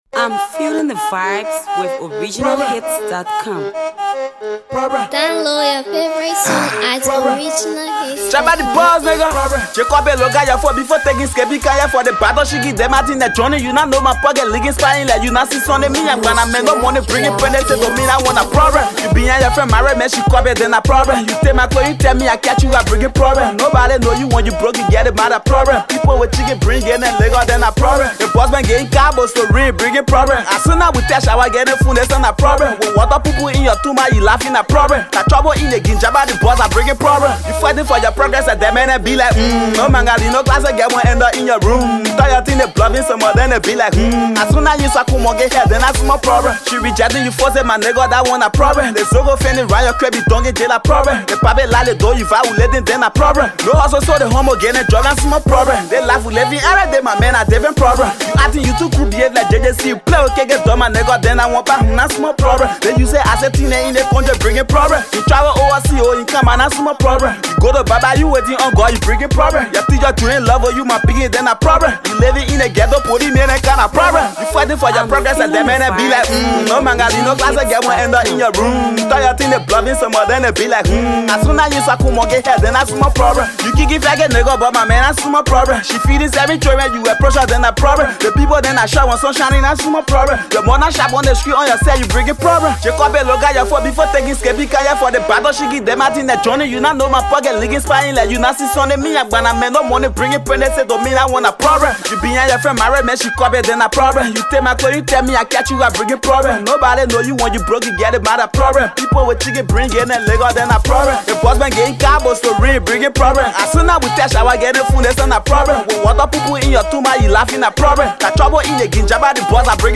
the talented Liberian rap artist